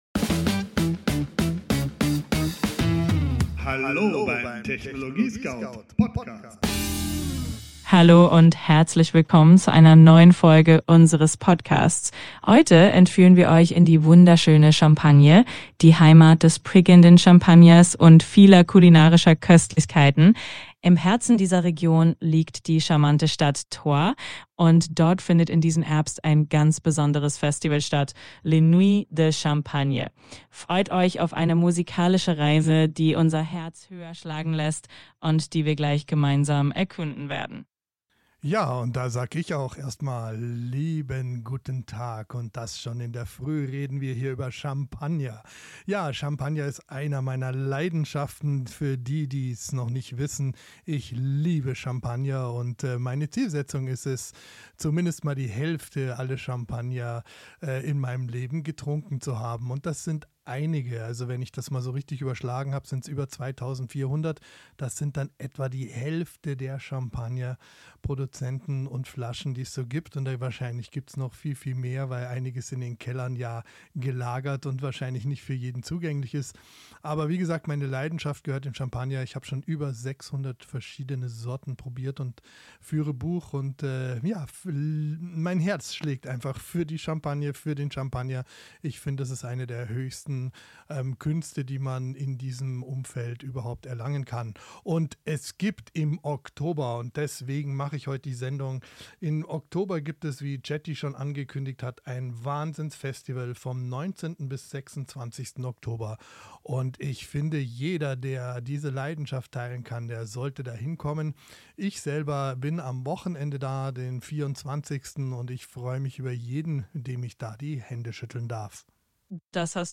"Live aus dem Studio des TechnologieScout